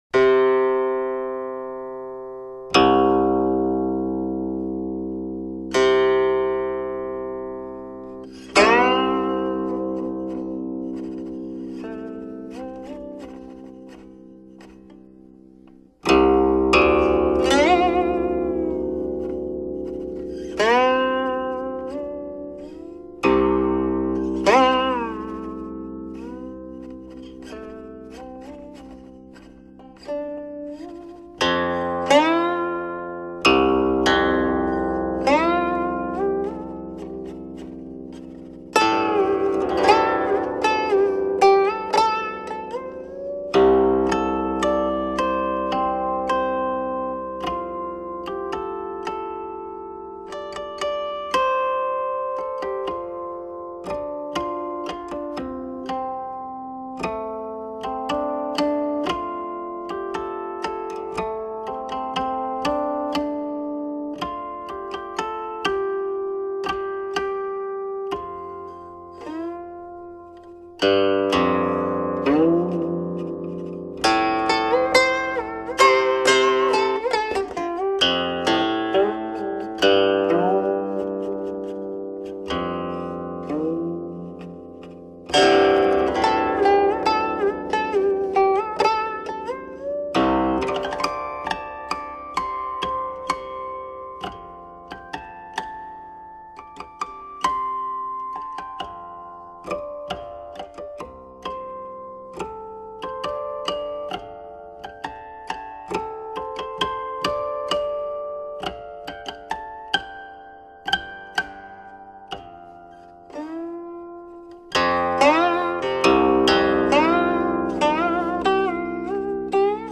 纯粹的声音 来自古老的神秘旋律